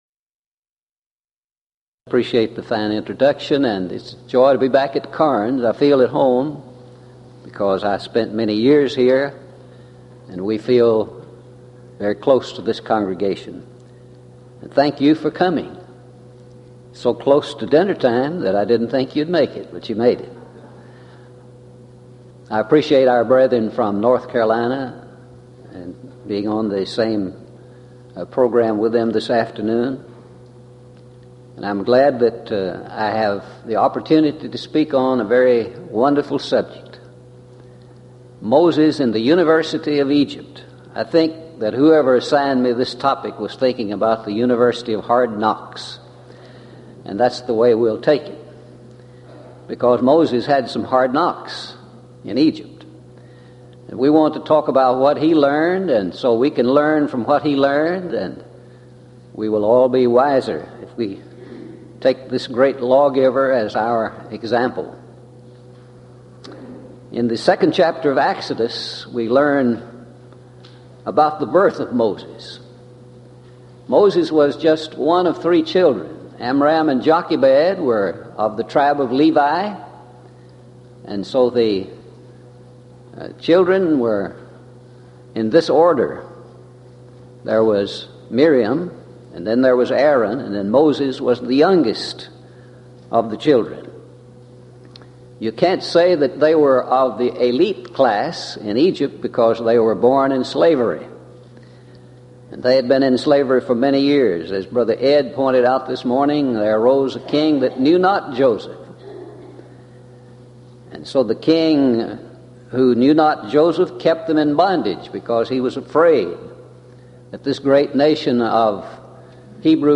Event: 1997 East Tennessee School of Preaching Lectures Theme/Title: Studies In The Book of Exodus